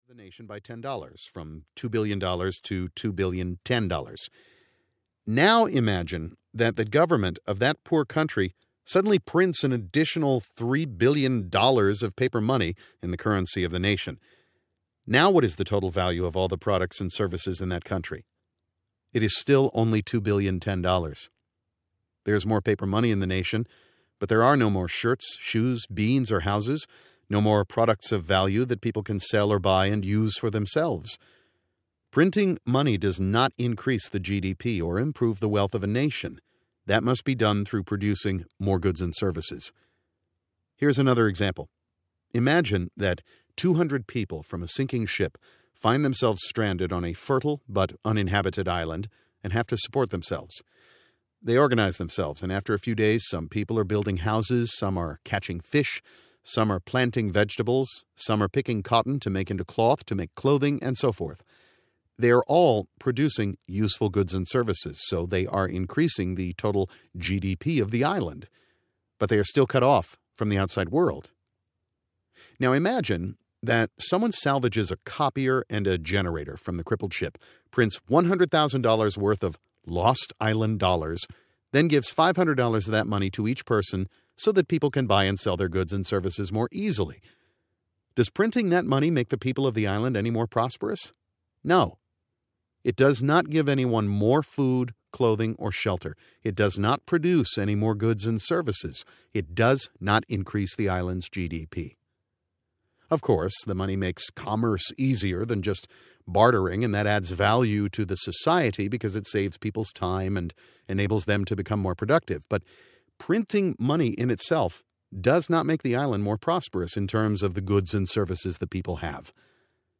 The Poverty of Nations Audiobook